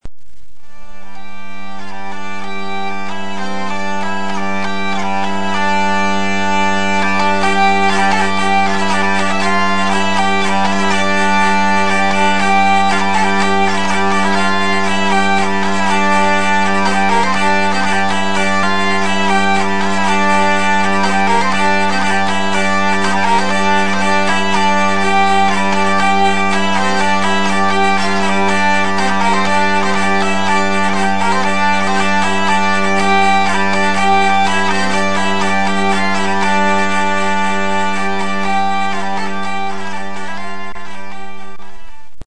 Con el fin de que el sonido de la zanfona no se pierda en los tiempos, hemos puesto a disposición de todos aquellos que lo deseen un archivo que nos muestra el mágico sonido que produce la zanfona,
Archivo que muestra el sonido característico e inolvidable de la zanfona
zanfona.mp3